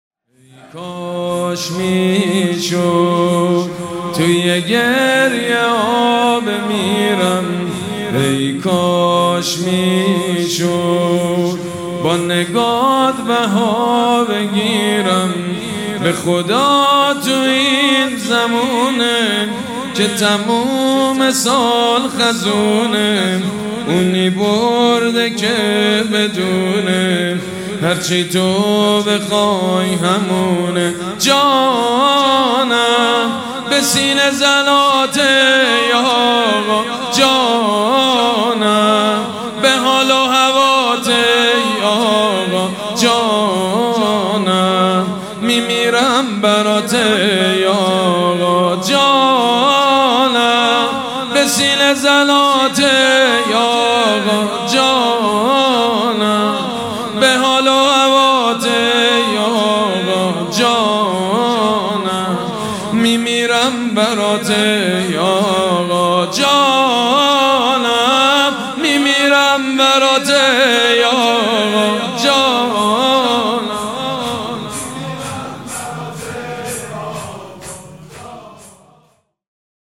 نوحکده
کربلایی سید مجید بنی فاطمه